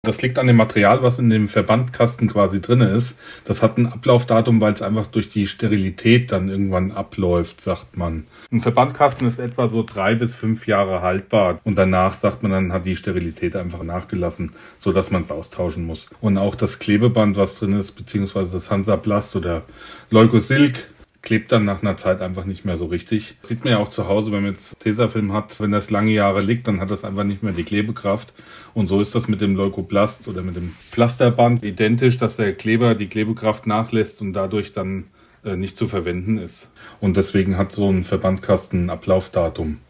Experte erklärt: Warum haben Verbandkästen ein Ablaufdatum?